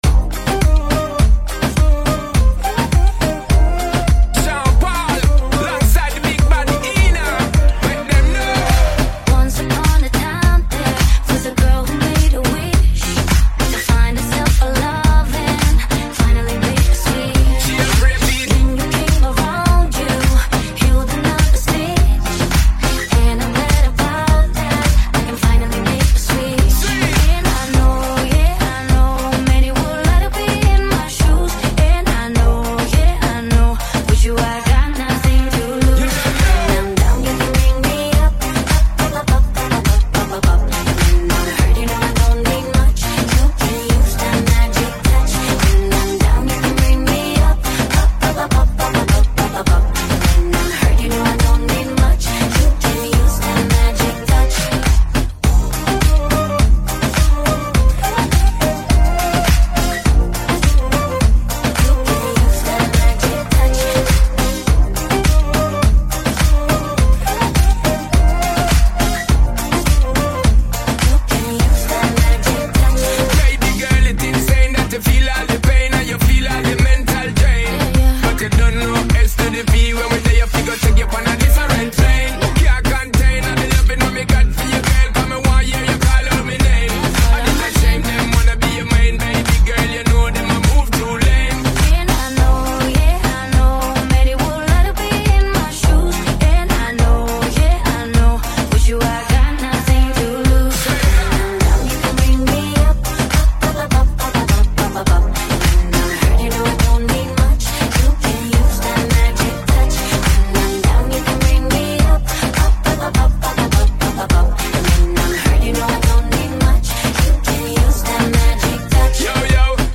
Reggaeton Mix